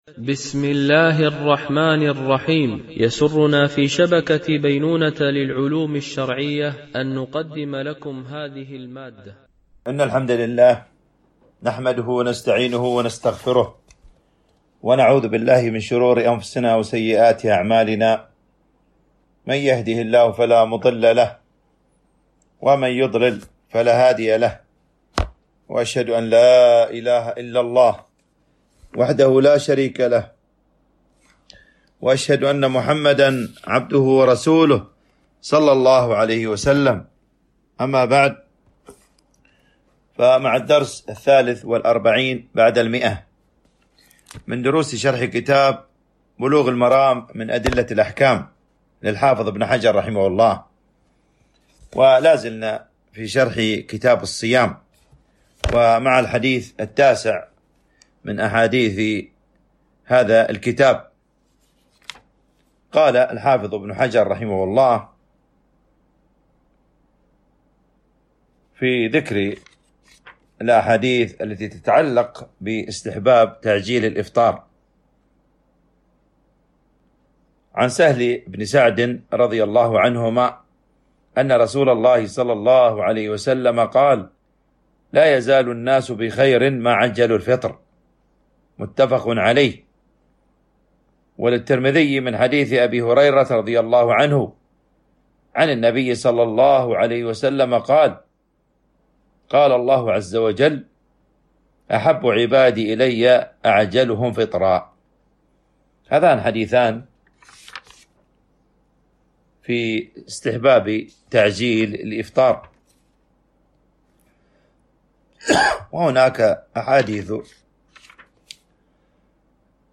شرح بلوغ المرام من أدلة الأحكام - الدرس 143 ( كتاب الصيام - الجزء الثالث - الحديث 658 - 662 )
التنسيق: MP3 Mono 44kHz 64Kbps (CBR)